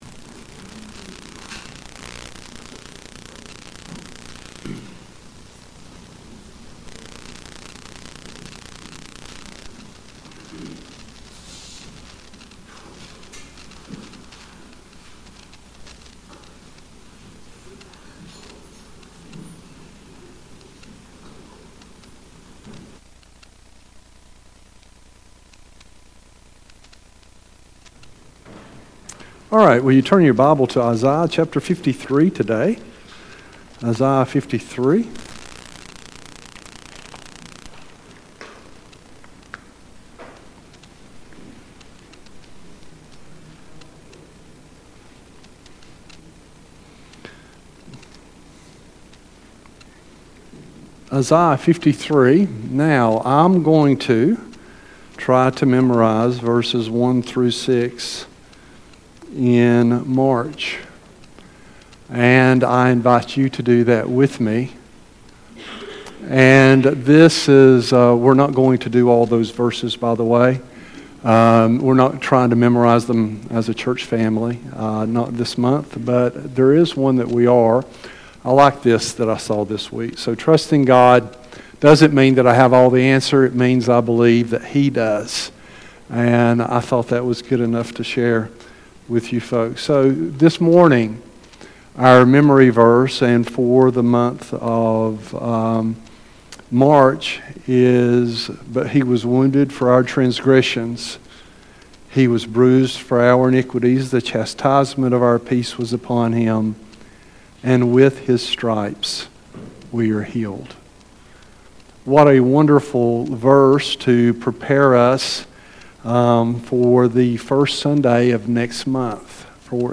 Passage: Isaiah 53:1-12 Service Type: Sunday AM The final version of this service